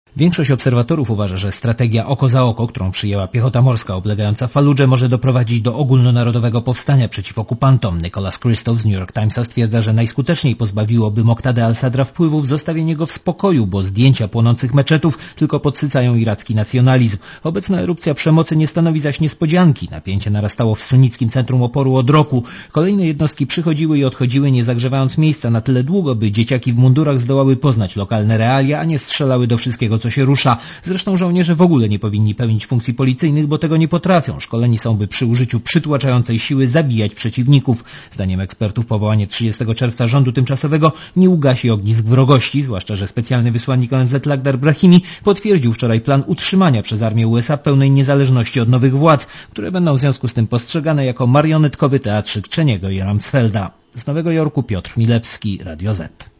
Korespondencja z USA